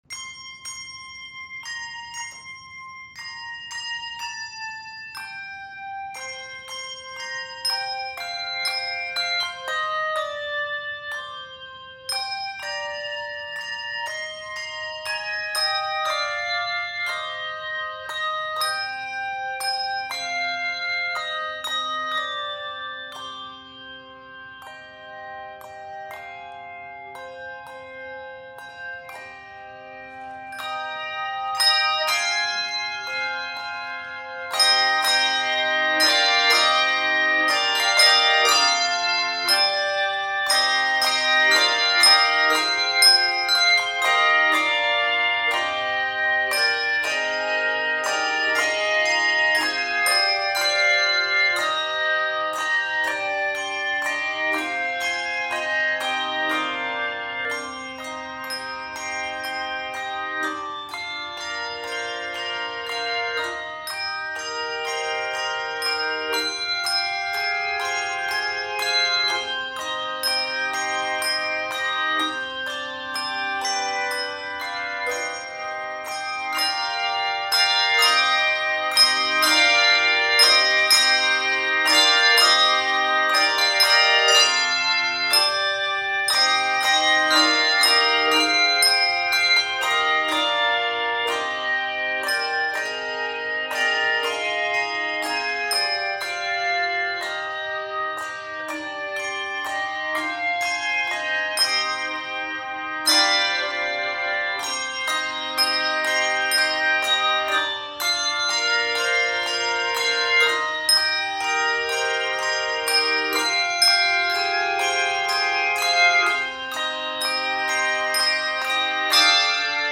Key of c minor.